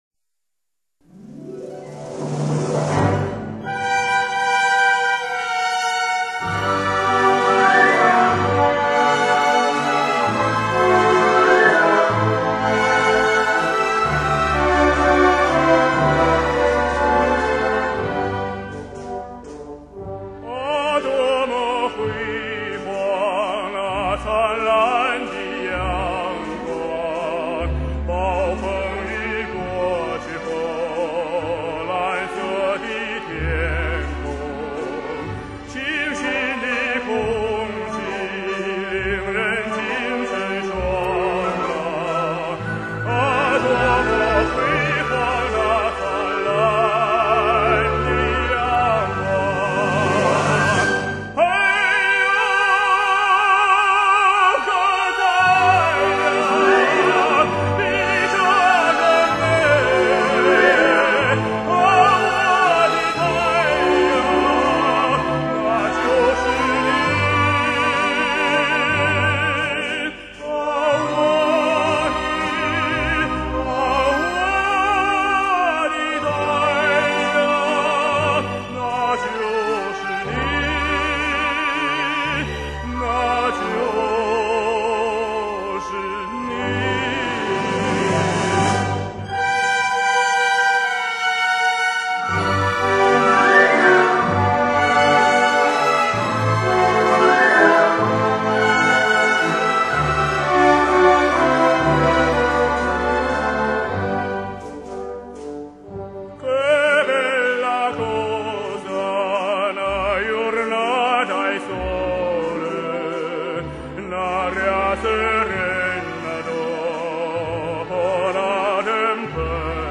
历历岁月，世纪回望，世界民歌，乐韵悠长。